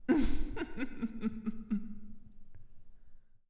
effect__ghost_laugh.wav